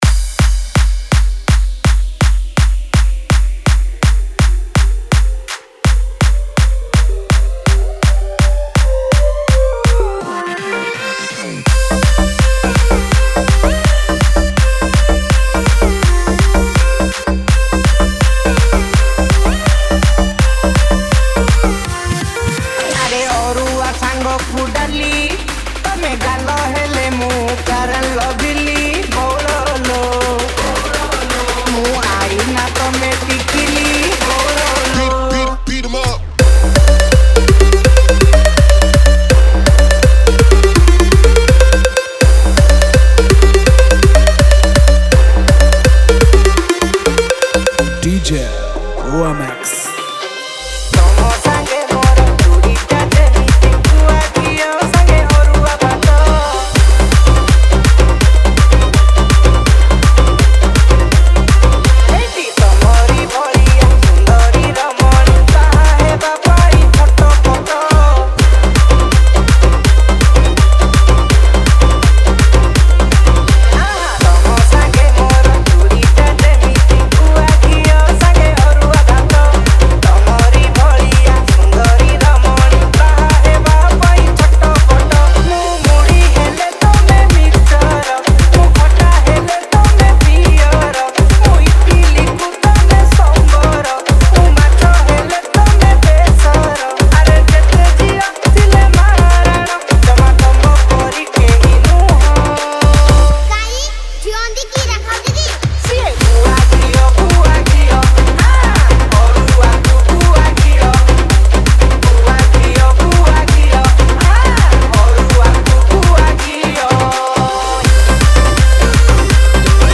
Category: Holi Special Odia Dj Remix Songs